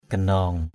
/ɡ͡ɣa-nɔ:ŋ/ (cv.) ginaong g{_n”